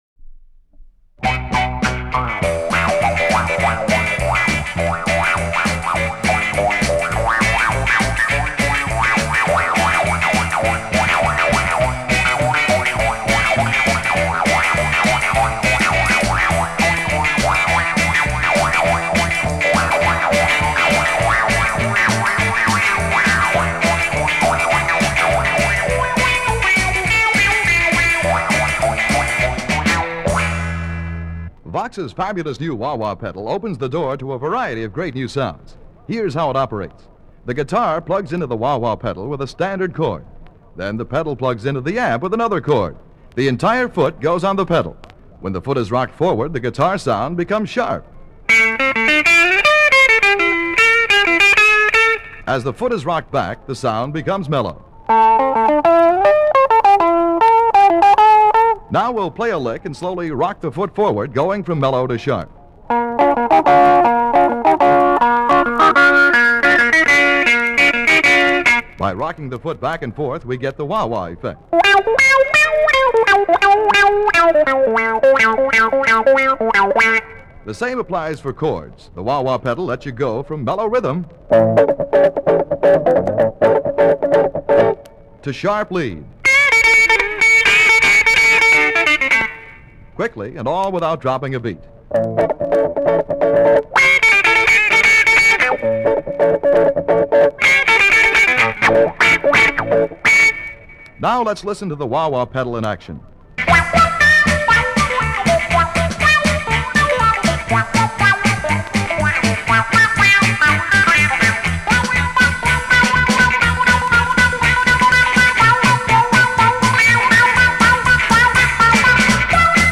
Vox Wah-Wah Ad (5 minutes long and worth listening to every minute):
36-vox-wah-wah-promo-spot.mp3